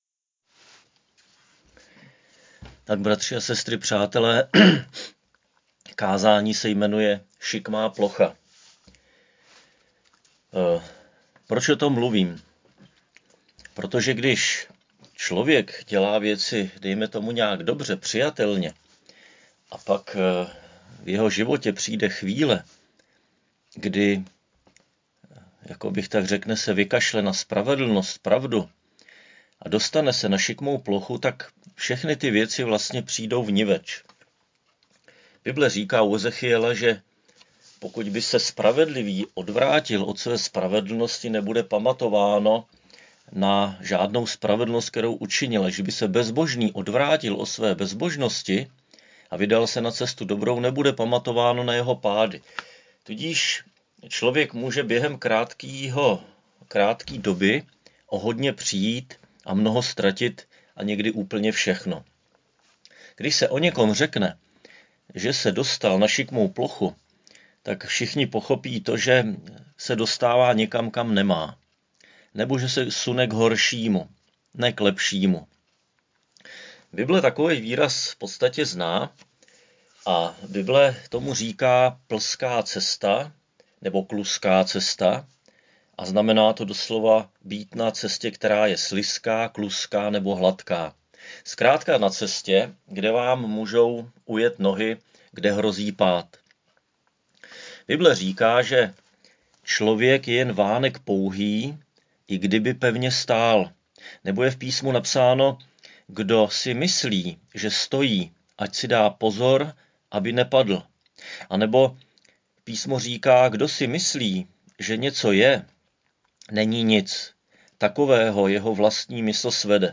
Křesťanské společenství Jičín - Kázání 31.5.2020